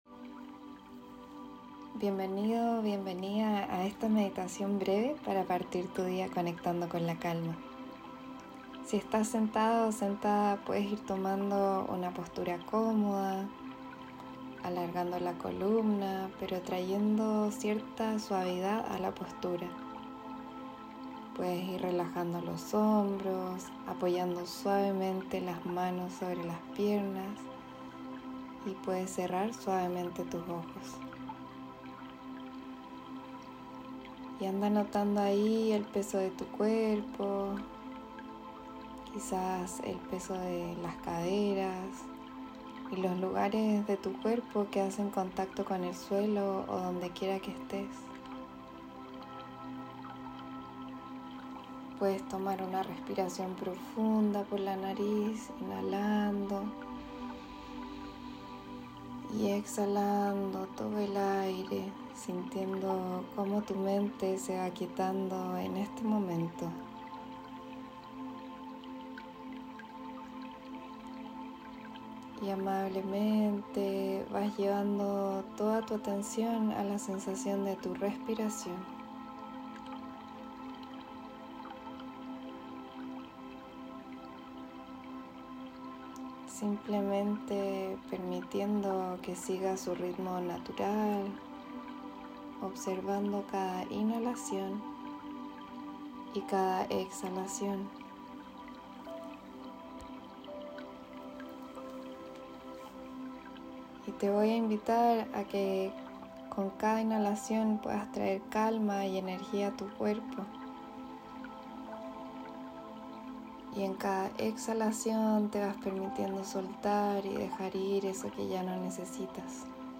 Meditación guiada para comenzar el día con calma | Mindful Growth